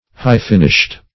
\High"-fin`ished\